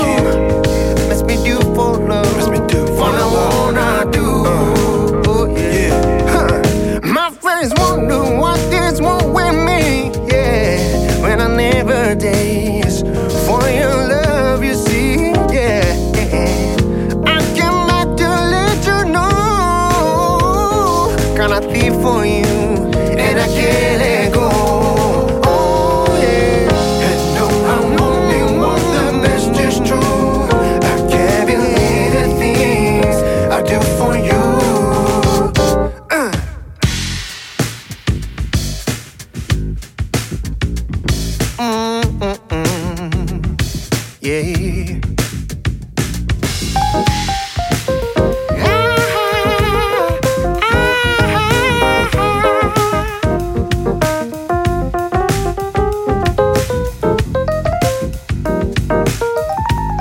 ジャンル(スタイル) JAZZ / POP JAZZ